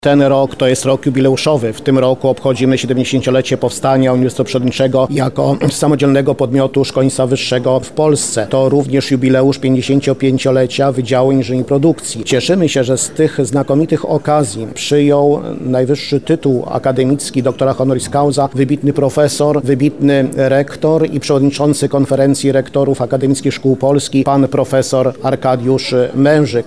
Uroczystość odbyła się w Centrum Kongresowym uczelni z udziałem władz akademickich, przedstawicieli świata nauki i gości zagranicznych.